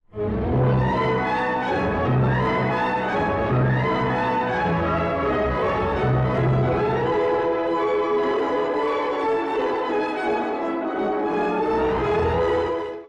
つむじ風のような出だしから、急激な踊りの主題が現れます。
上昇音型の数々はまるで祝祭のよう。